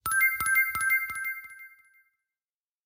Звуки всплывающего окна